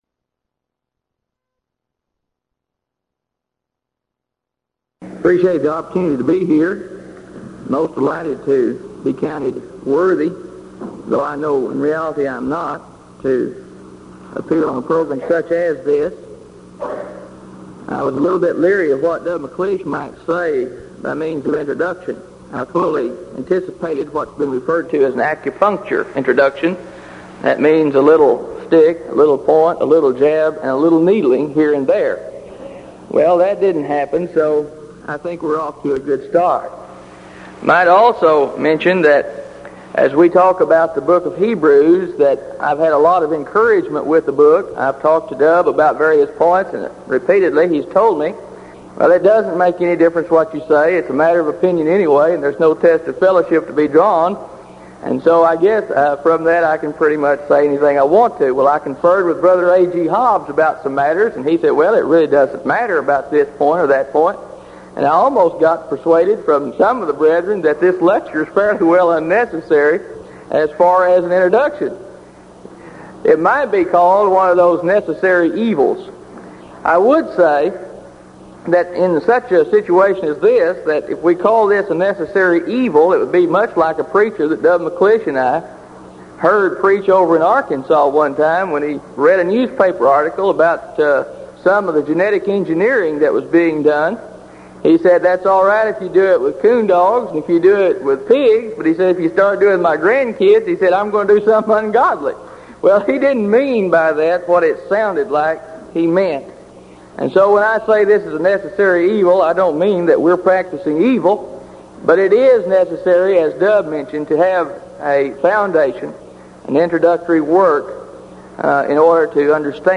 Event: 1983 Denton Lectures
lecture